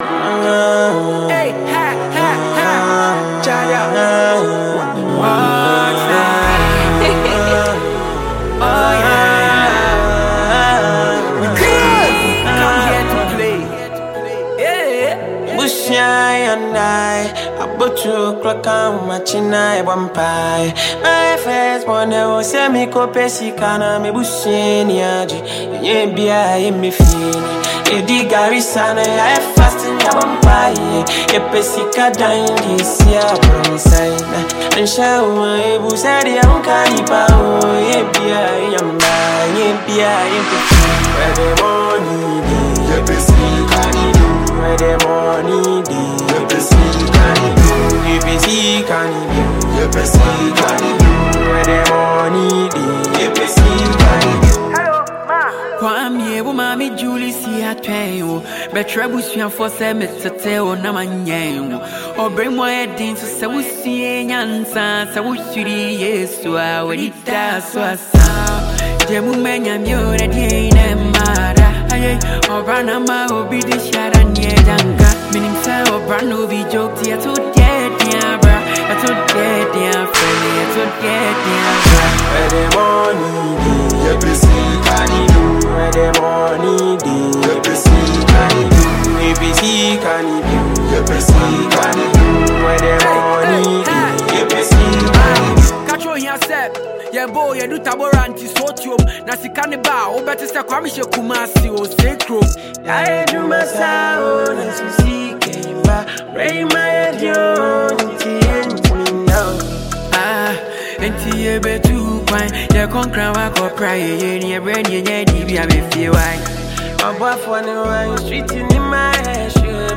Ghanaian singer